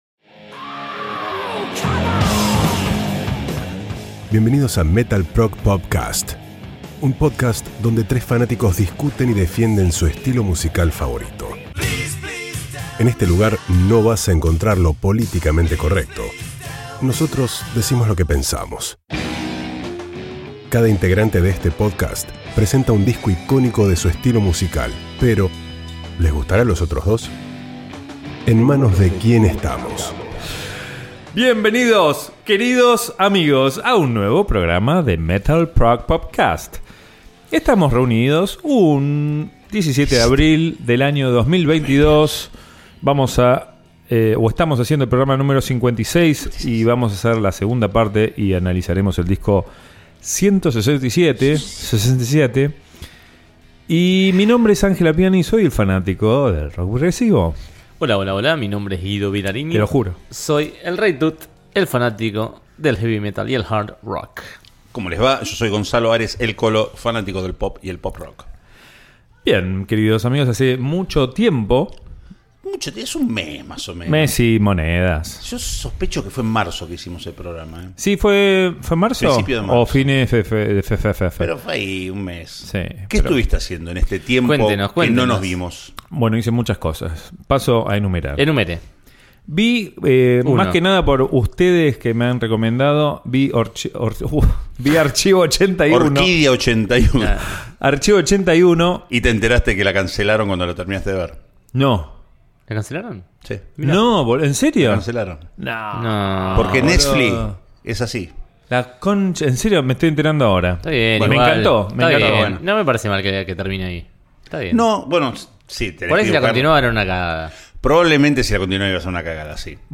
En MetalProgPop Cast nos juntamos 4 amigos para hablar y discutir sobre música.